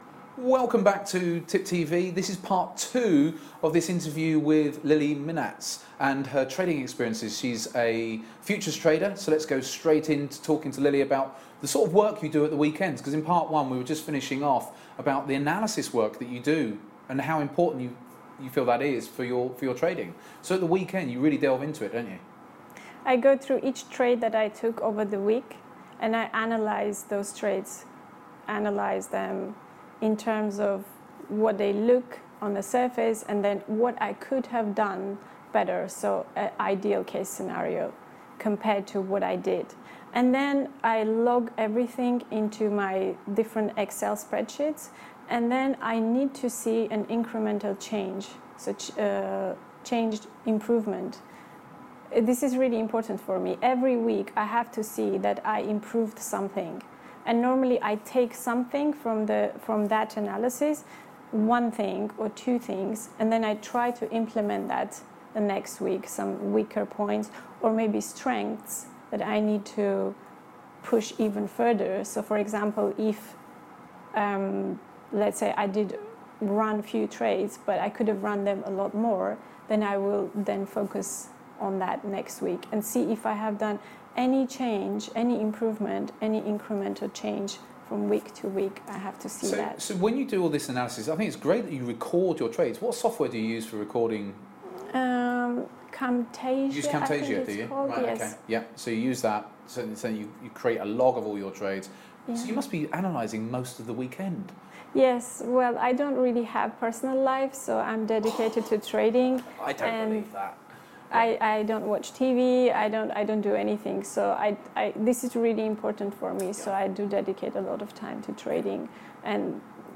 Interview with traders: Need for discipline and adapting to change (Part 2) – EzeeTrader